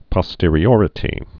(pŏ-stîrē-ôrĭ-tē, -ŏr-, pō-)